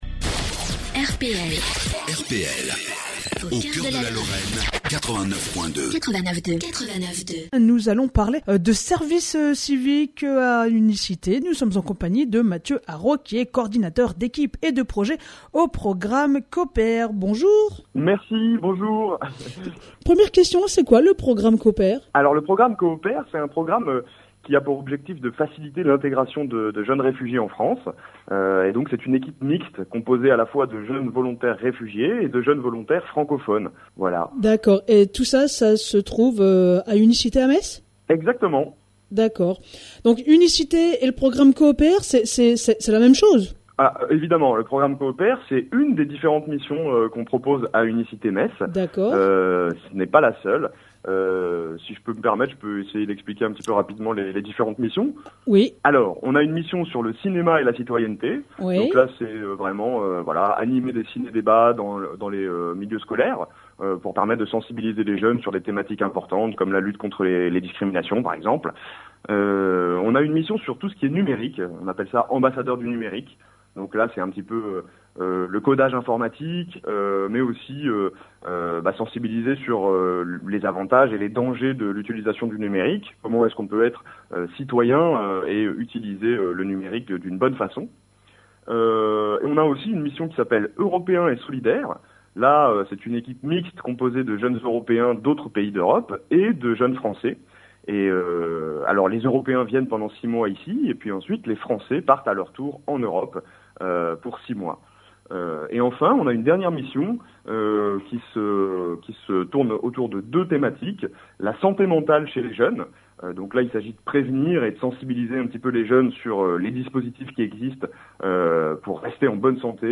Interview donnée à « RPL », la Radio du Pays Lorrain (89.2) sur la mobilisation des jeunes et les différentes missions de service civique proposées par Unis Cité Metz